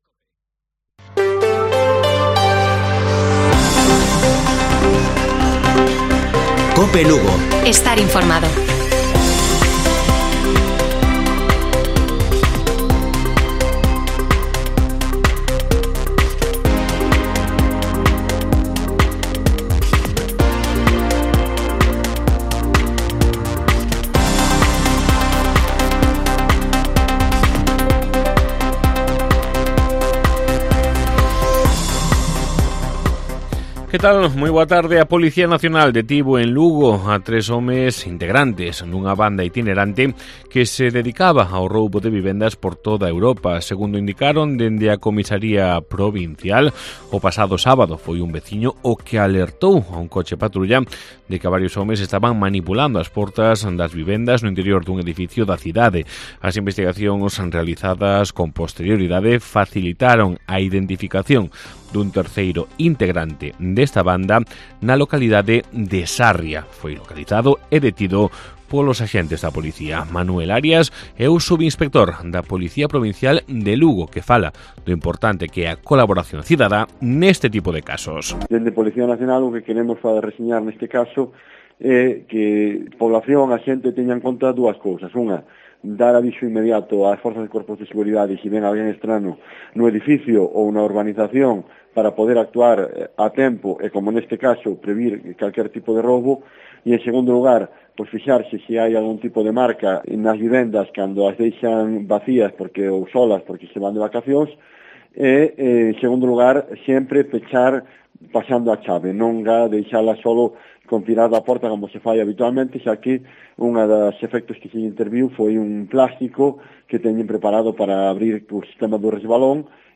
Informativo Mediodía de Cope Lugo. 30 de agosto. 14:20 horas